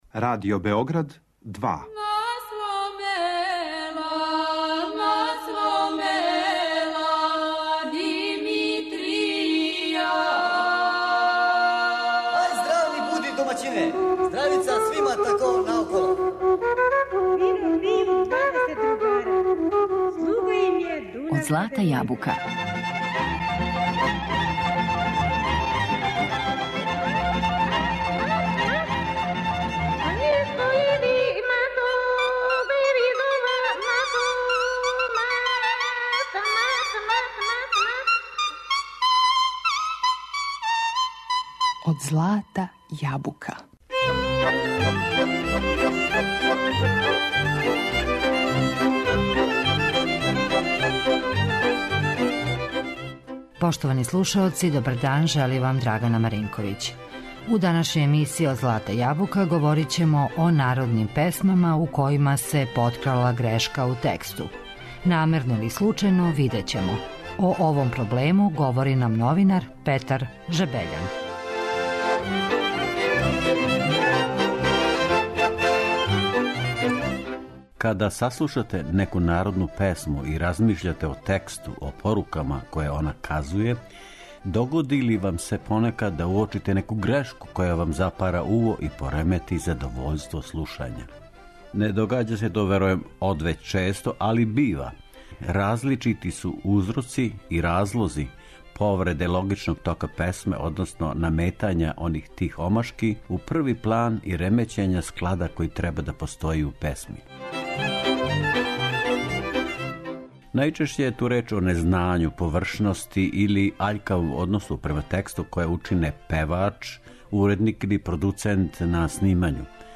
Намерно, или случајно, видећемо и чути у музичким примерима које смо одабрали.